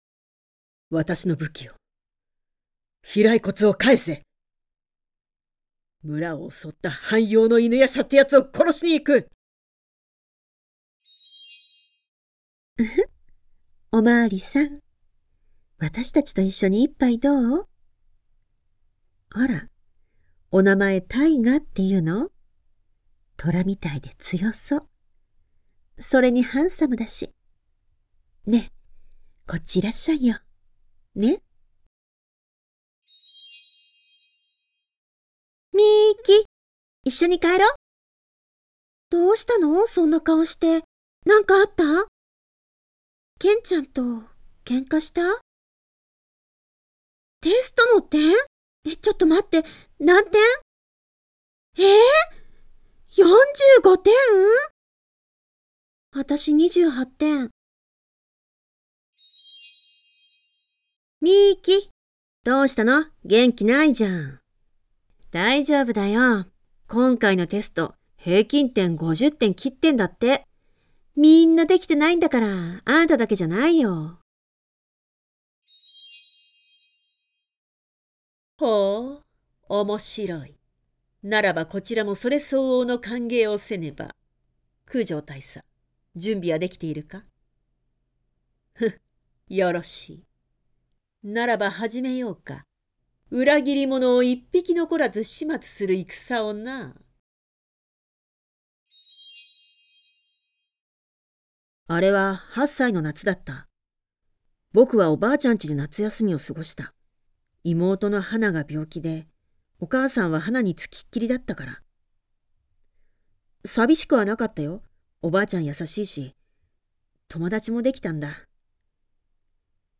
Professional Japanese voiceover talent.
For game , animation / Various ages, various roles